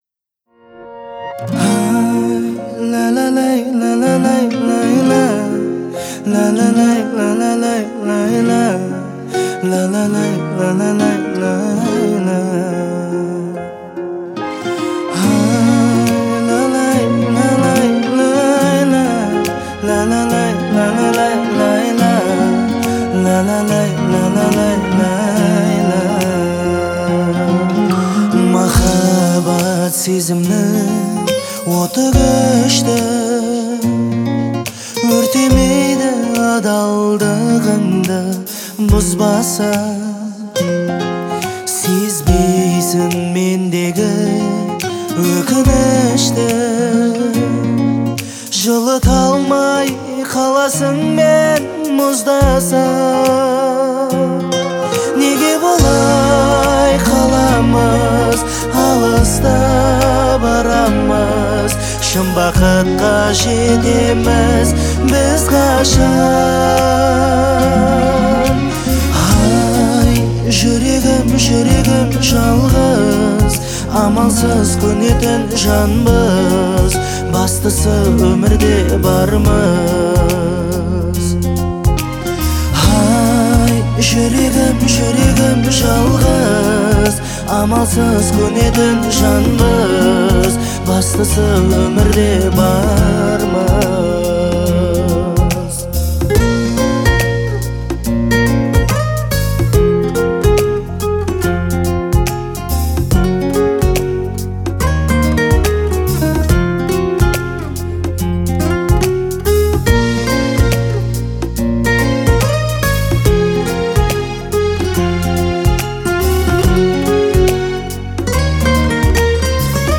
трогательная песня
через мелодичные вокальные партии и выразительное исполнение